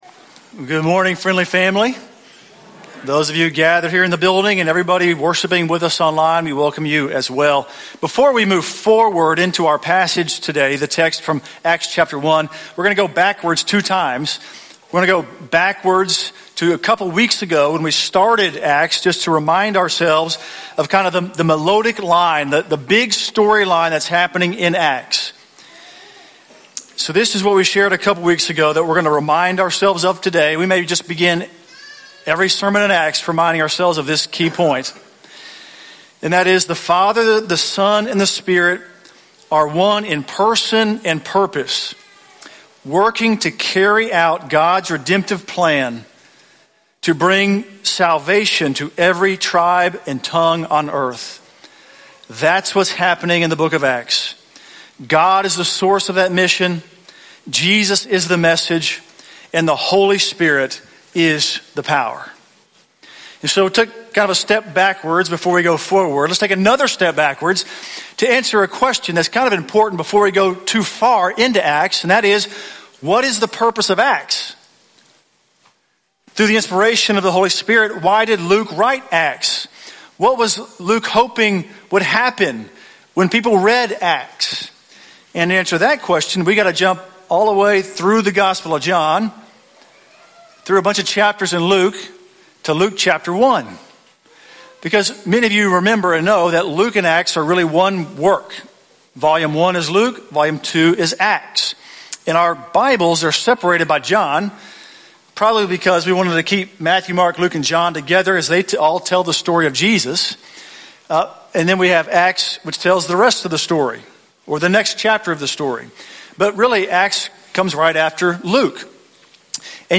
The Spirit Acts: Witnesses of the Resurrection - English Sermons - 10:15